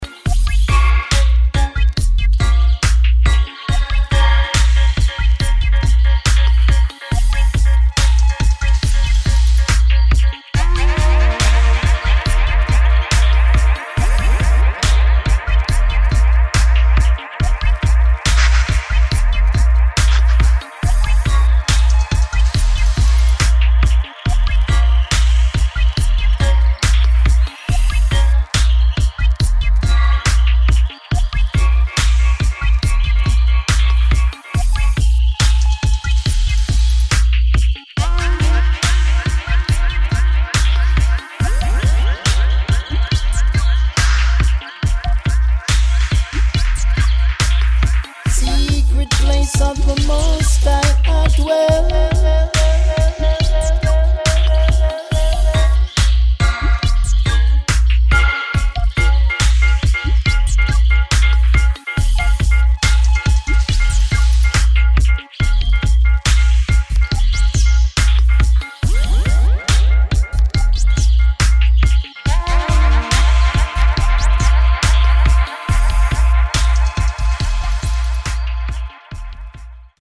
[ DUB / REGGAE / DUBWISE ]